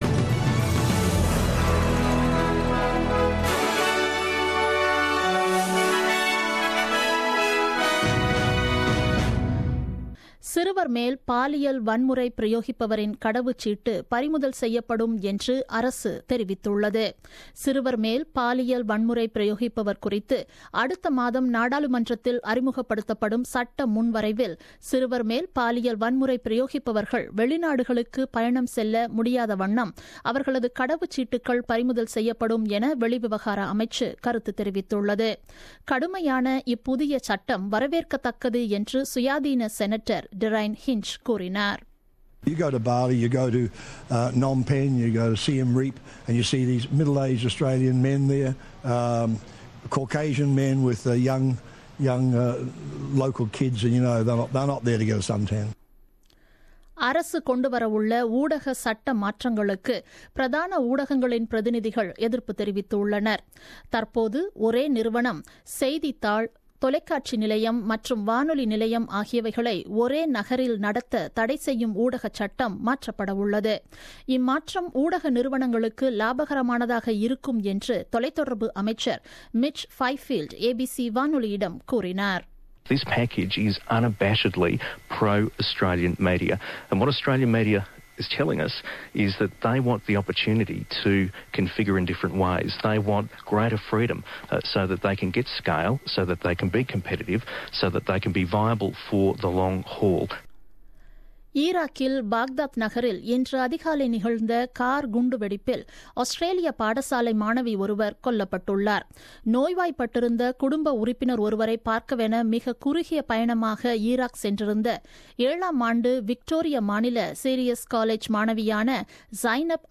The news bulletin broadcasted on 31st May 2017 at 8pm.